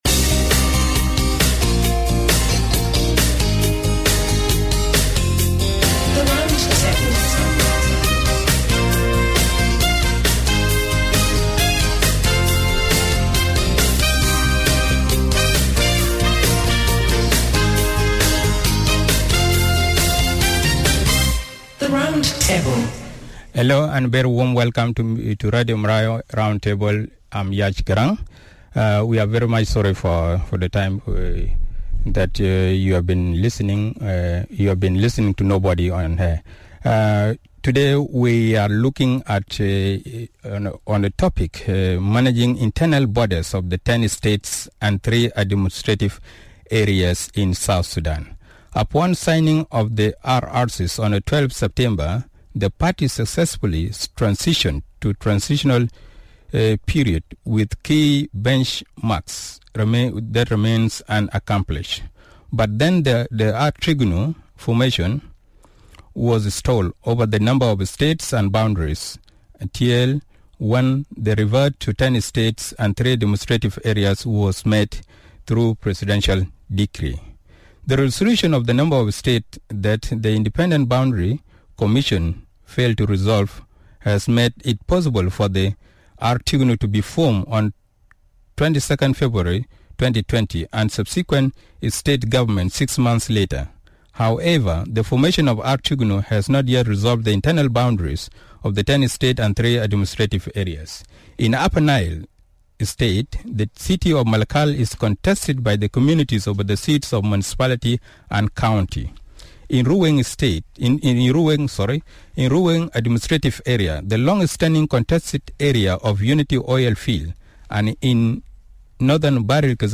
Roundtable discussion: Managing internal borders of the ten States and three Administrative areas